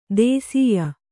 ♪ dēsīya